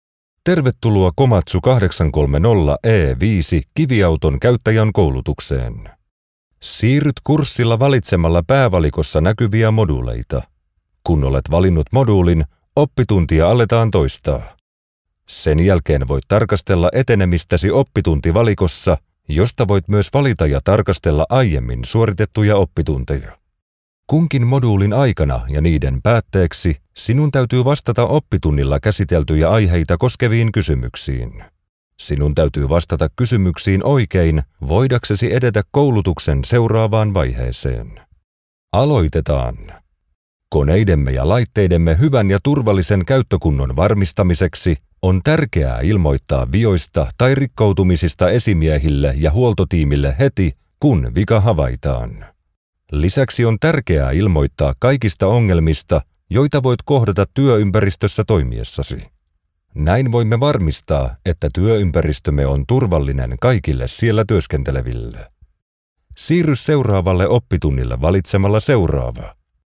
Diep, Vertrouwd, Commercieel, Zakelijk, Warm
E-learning